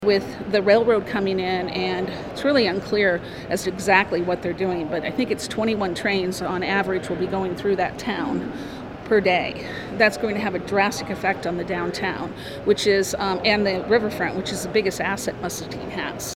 The U.S. Surface Transportation Board extended its public comment meeting into the night as speakers lined up to oppose Canadian Pacific’s expansion all the way to Mexico.